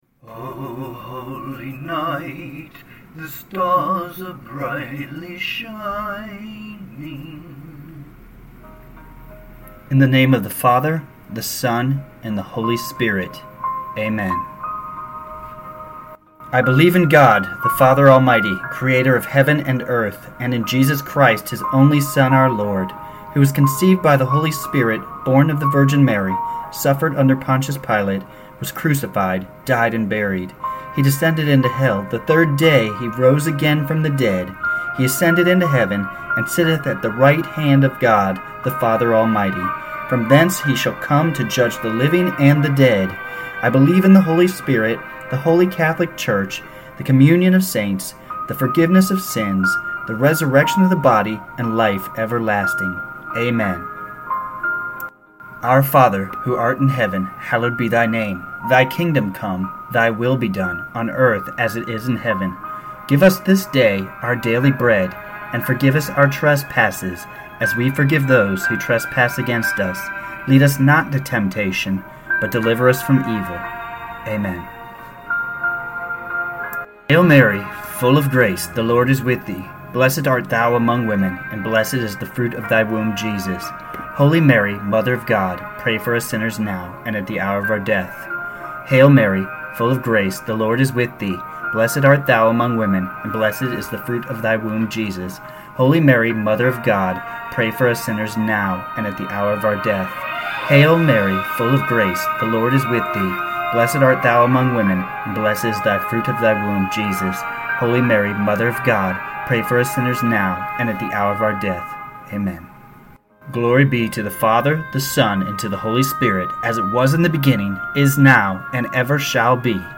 Special rosary prayer to celebrate the power of Christ the Lord.